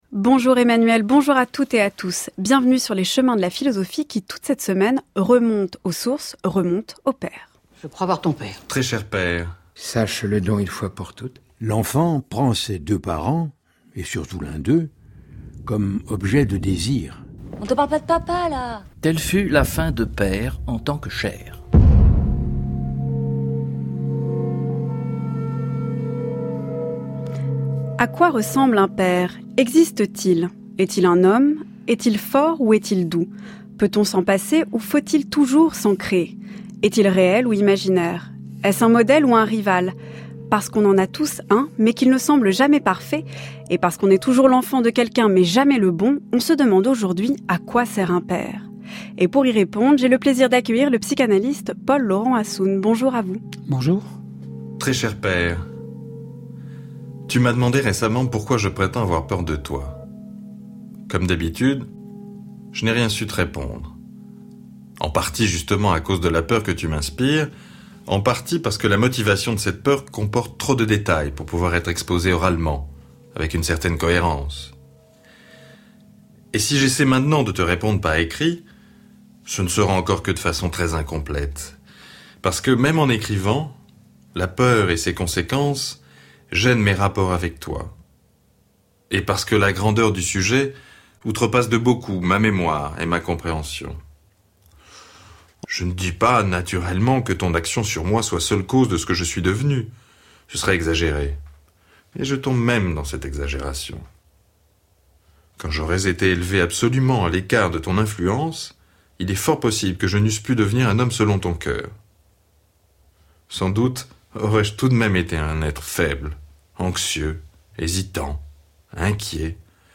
L’invité du jour